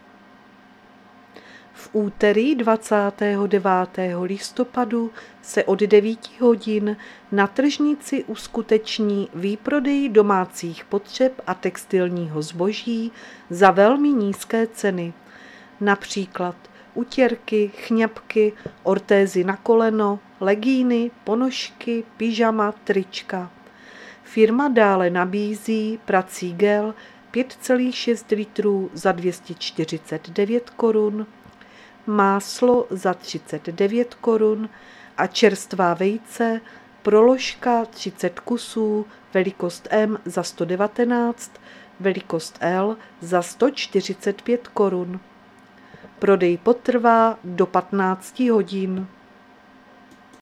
Záznam hlášení místního rozhlasu 28.11.2022